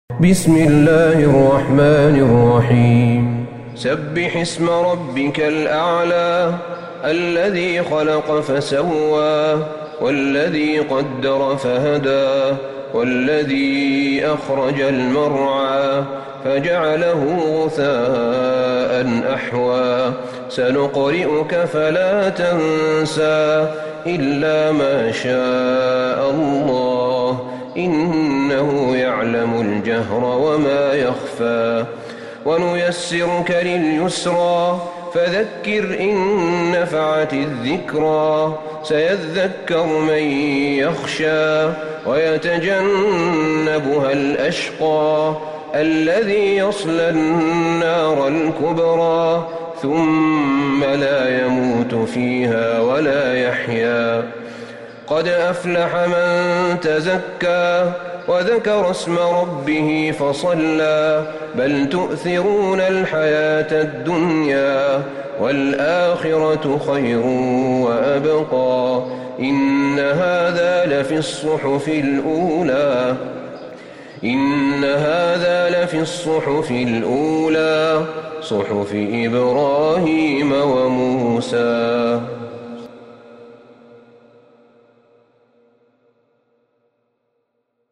سورة الأعلى Surat Al-Ala > مصحف الشيخ أحمد بن طالب بن حميد من الحرم النبوي > المصحف - تلاوات الحرمين